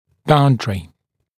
[‘baundrɪ][‘баундри]граница, предел